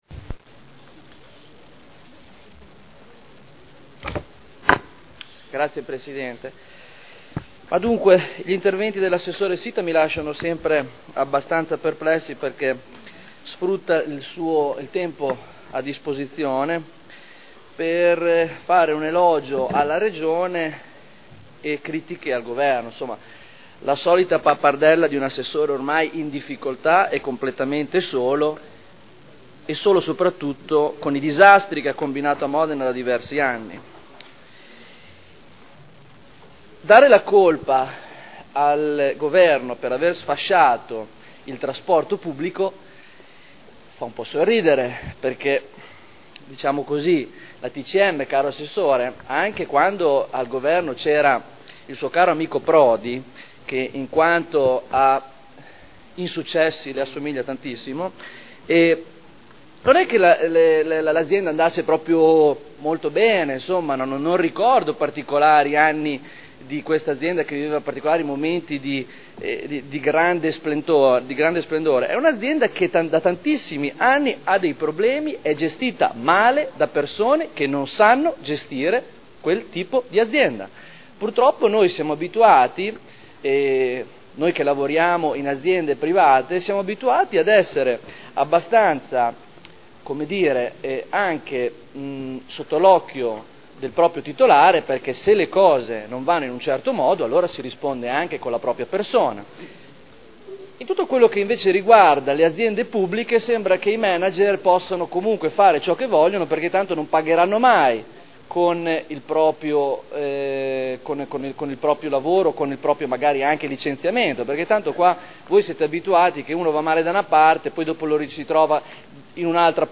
Seduta del 21/02/2011. Indirizzi per la gestione del trasporto pubblico locale a seguito del patto per il trasporto pubblico regionale e locale in Emilia Romagna per il triennio 2011/2013 – aumenti tariffari per il Comune di Modena – Approvazione discussione
Audio Consiglio Comunale